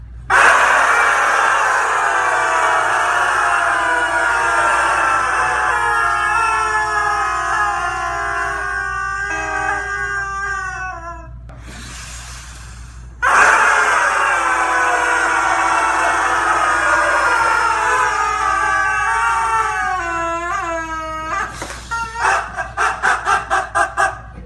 dat-duck.mp3